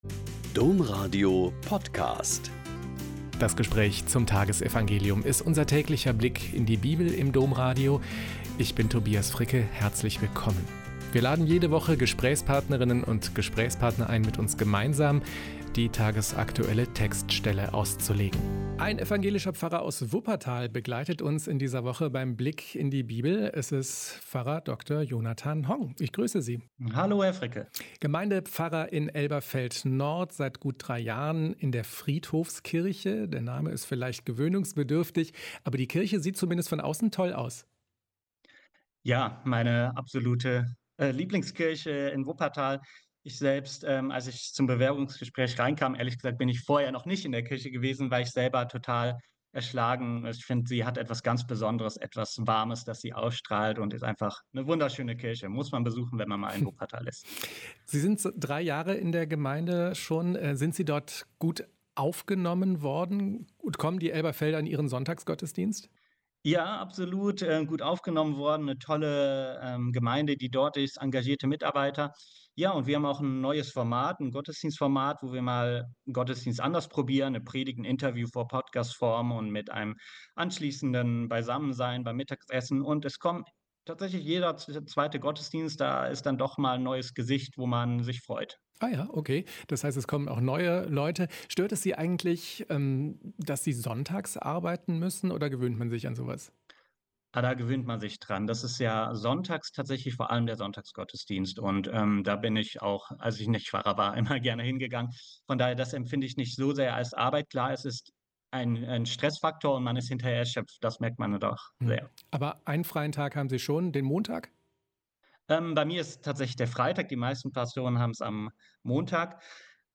Mk 3,22-30 - Gespräch